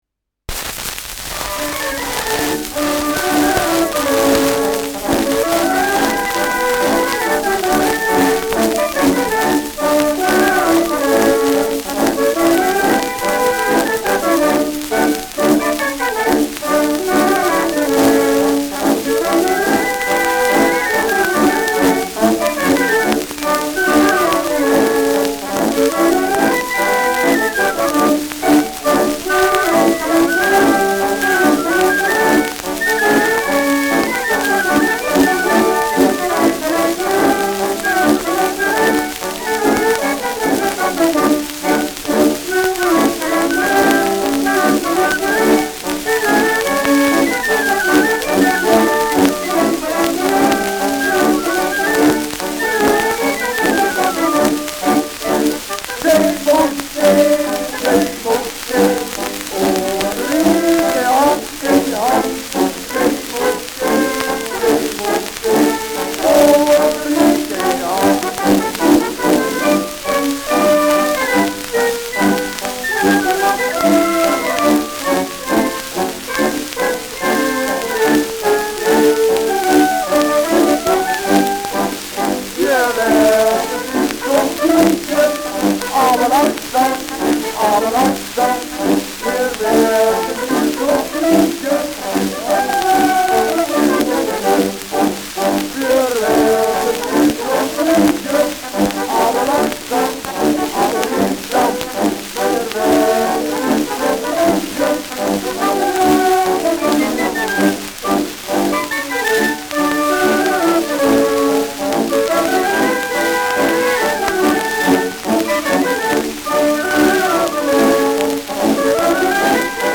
Schellackplatte
präsentes bis starkes Rauschen : präsentes Knistern : abgespielt : leichtes Leiern : Nadelgeräusch
Stadtkapelle Fürth (Interpretation)